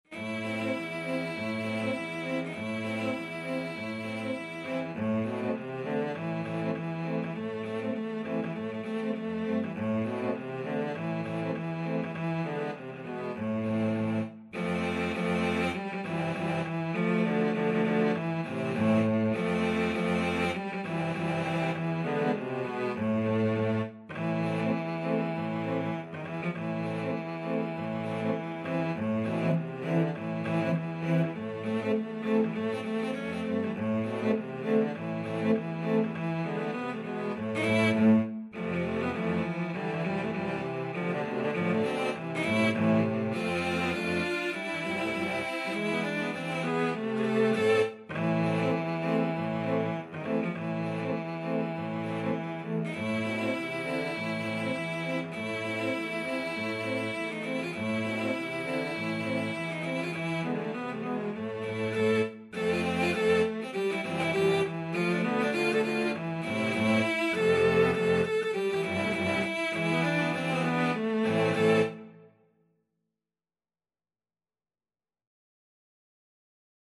Free Sheet music for Cello Quartet
Cello 1Cello 2Cello 3Cello 4
A minor (Sounding Pitch) (View more A minor Music for Cello Quartet )
Firmly, with a heart of oak! Swung = c.100
2/2 (View more 2/2 Music)
Traditional (View more Traditional Cello Quartet Music)
world (View more world Cello Quartet Music)